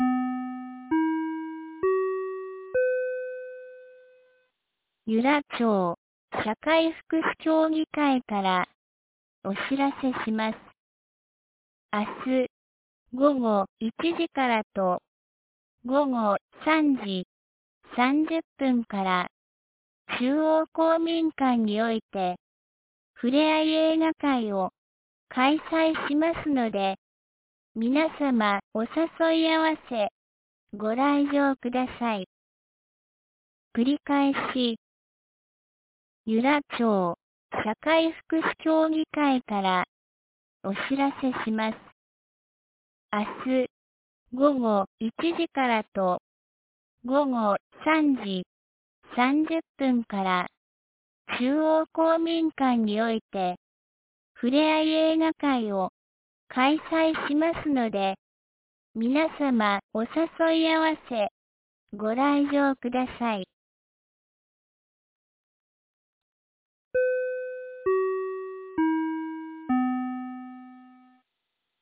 2019年08月09日 17時06分に、由良町より全地区へ放送がありました。